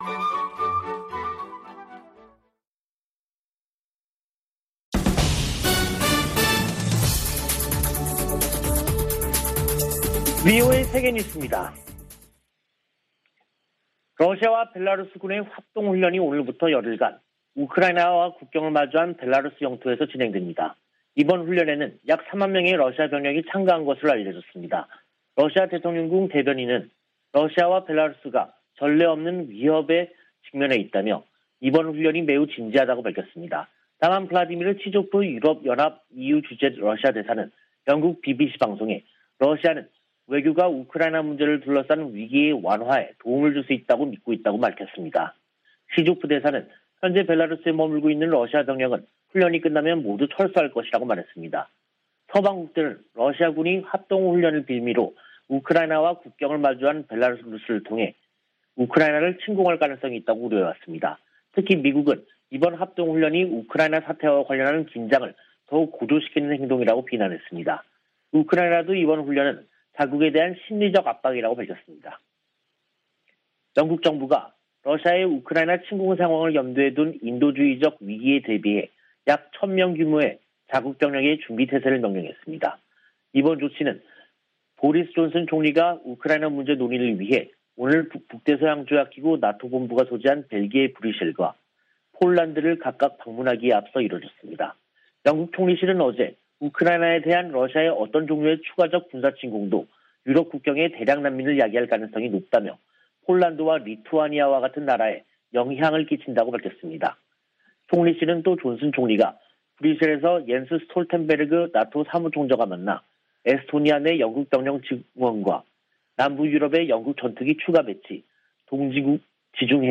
VOA 한국어 간판 뉴스 프로그램 '뉴스 투데이', 2022년 2월 10일 2부 방송입니다. 토니 블링컨 미 국무장관은 이번 주 미한일 외교장관 회동이 북한의 도전 등 의제를 전진시킬 중요한 순간이라고 밝혔습니다. 미국 전직 관리들은 3국 외교장관 회담에서 일치된 대북 메시지가 나오기를 희망하고 있습니다. 문재인 한국 대통령은 남북정상회담에 선결조건이 없지만, 대선 결과가 실현에 영향을 줄 것이라고 말했습니다.